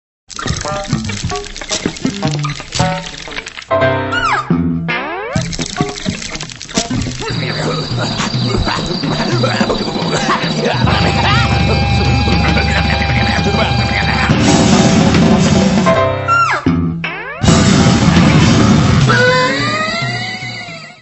saxofone alto, clarinete
bateria
guitarra
teclados
Área:  Novas Linguagens Musicais